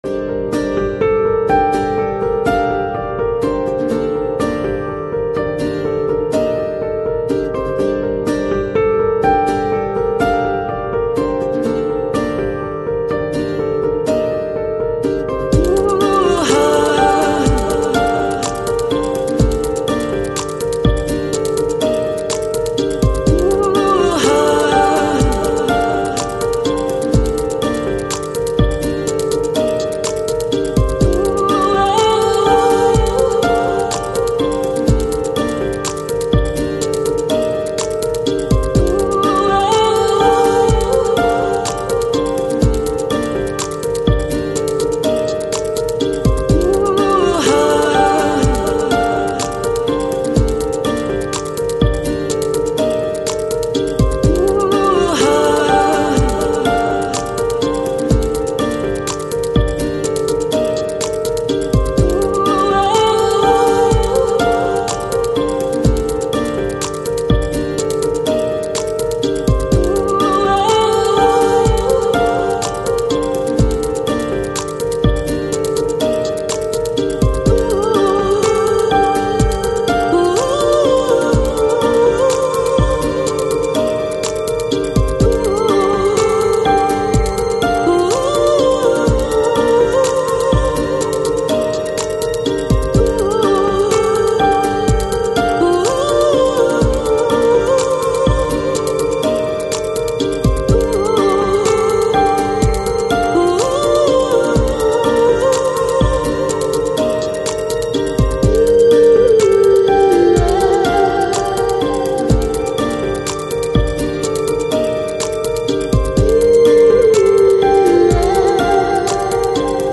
Lounge, Chill Out, Downtempo, Balearic